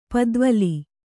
♪ padvali